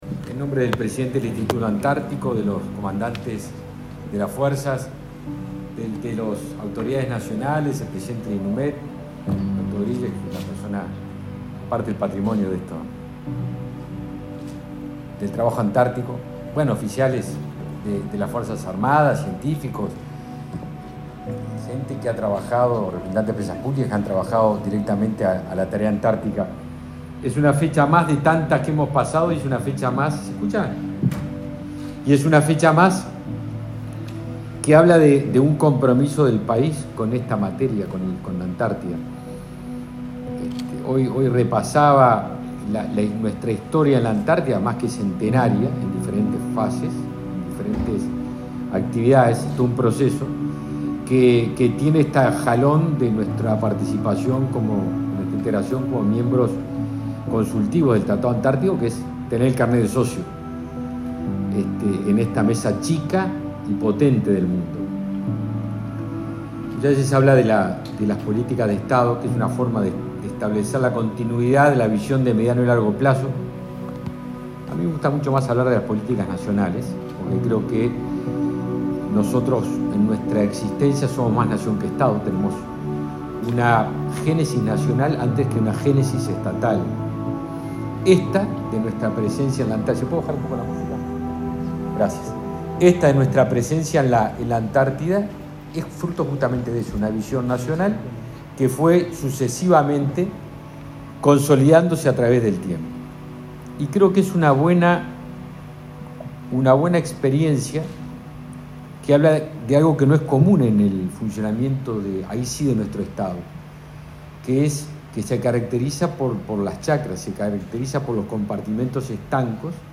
Palabras del ministro de Defensa Nacional, Javier García
El ministro de Defensa Nacional, Javier García, participó de la celebración del Día de la Antártida, realizada este martes 19 en el Instituto Militar